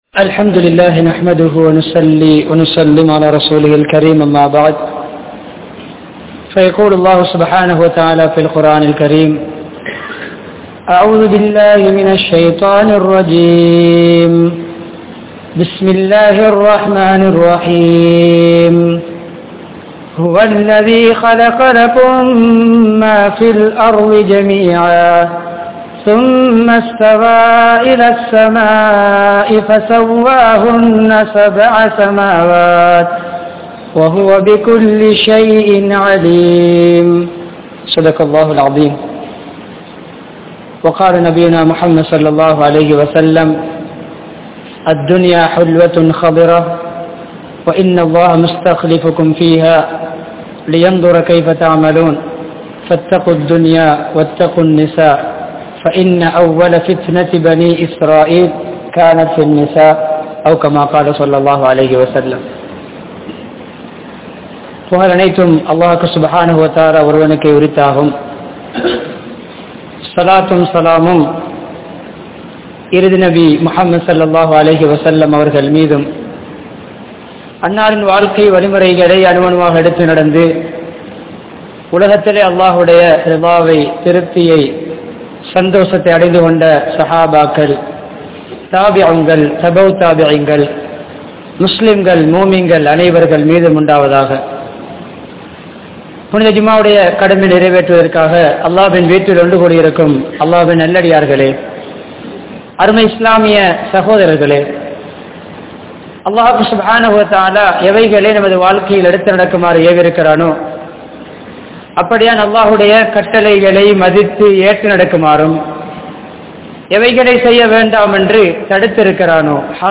Tholai Peasien Vifareethangal (தொலைபேசியின் விபரீதங்கள்) | Audio Bayans | All Ceylon Muslim Youth Community | Addalaichenai
Masjidhun Noor Jumua Masjidh